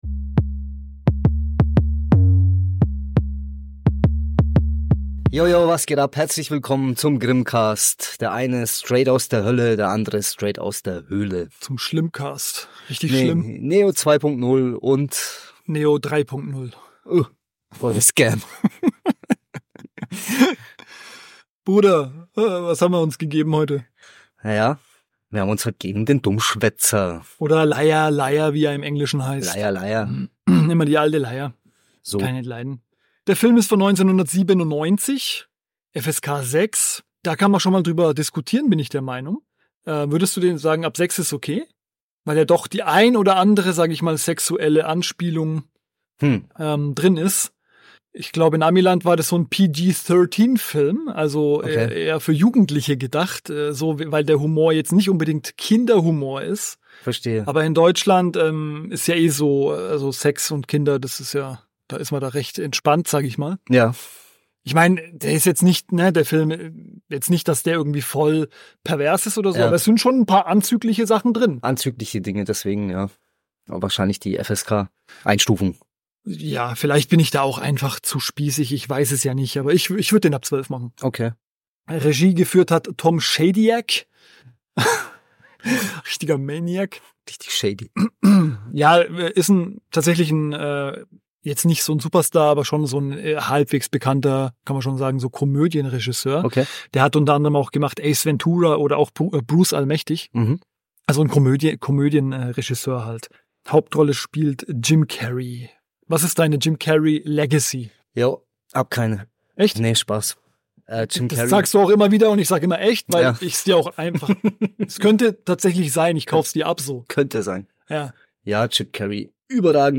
Der Dummschwätzer ist ein Komödienklassiker von Grimassen-König Jim Carrey. Im Gespräch der grimmigen Brüder